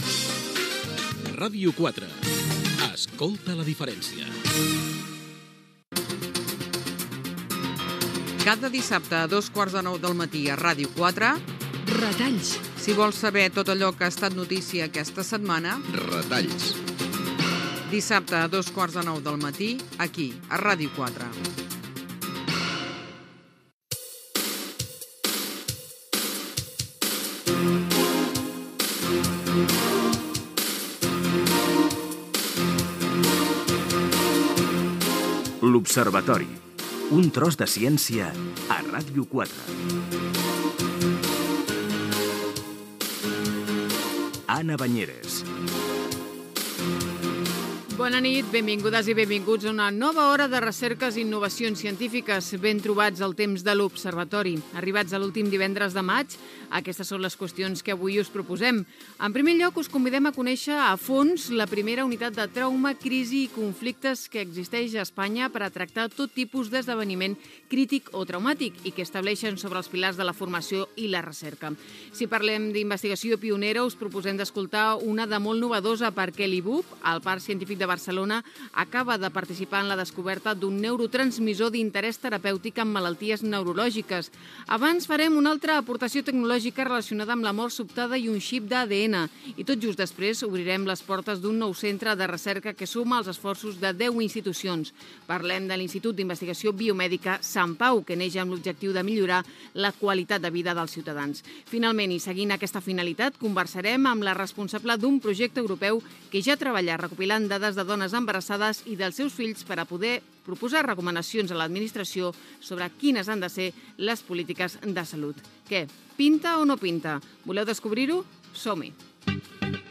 Indicatiu de l'emissora, promoció de "Retalls", careta del programa, sumari, indicatiu del programa, creació de la primera unitat de traumacrisis conflictes
Divulgació
FM